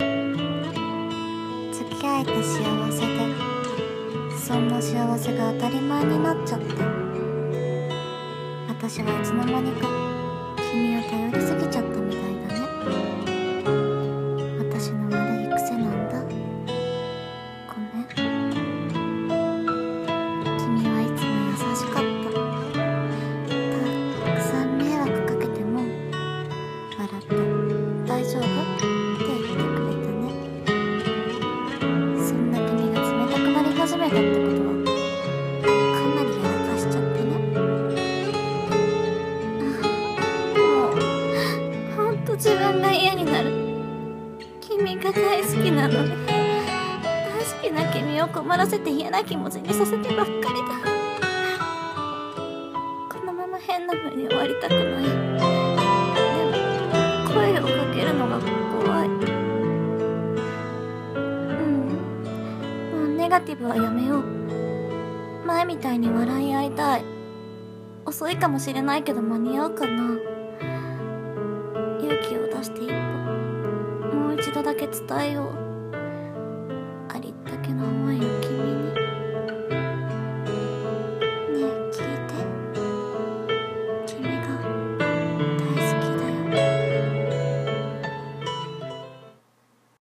【声劇 朗読】ごめんと大好きを